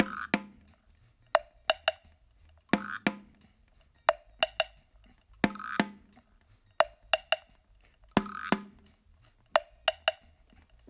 88-PERC5.wav